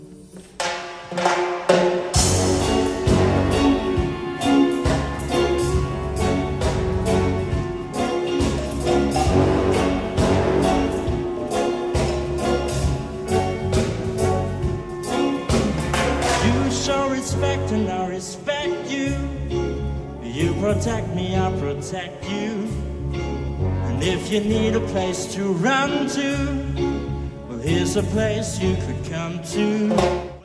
Gattung: Solo für Gesang und Symphonisches Blasorchester
Besetzung: Blasorchester